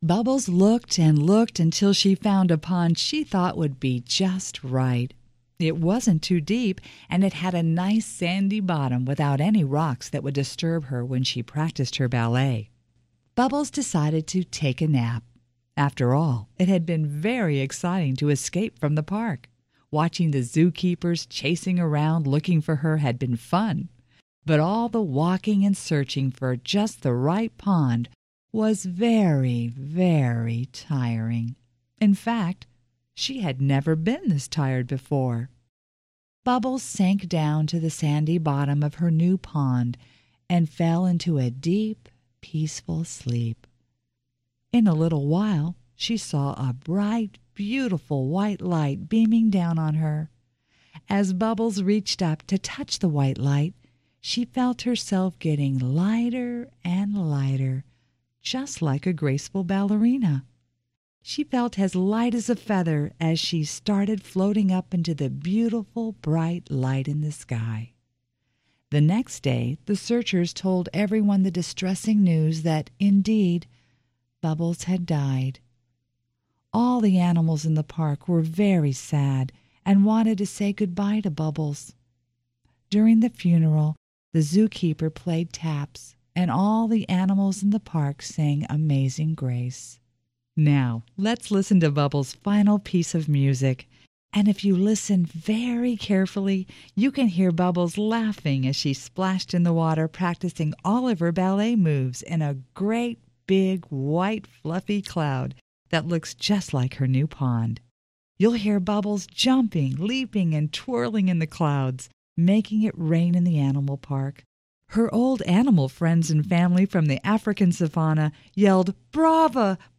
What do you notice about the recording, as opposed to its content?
(Studio Recording, 1978)